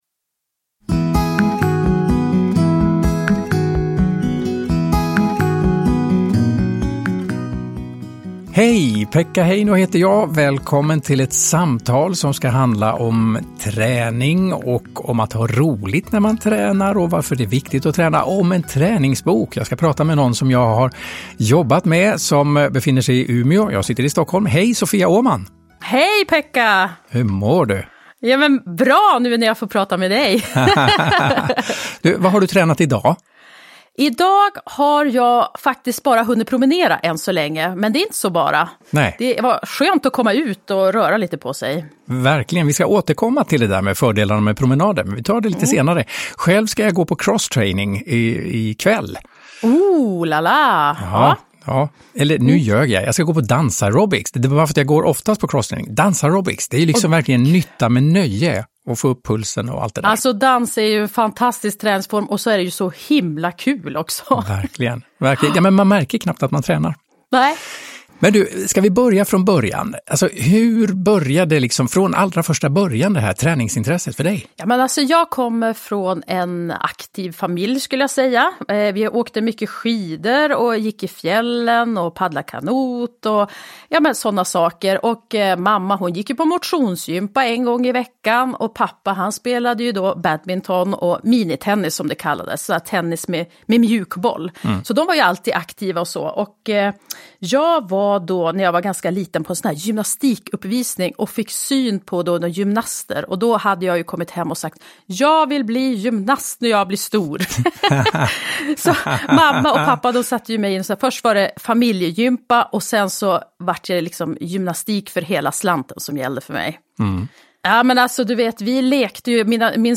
Intervju med Sofia Åhman – Ljudbok – Laddas ner
I ett samtal med Pekka Heino berättar Sofia om kärleken till träning och hälsa - och om hur det är att bli folkkär.
Uppläsare: Sofia Åhman, Pekka Heino